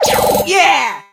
jackie_drill_lead_vo_03.ogg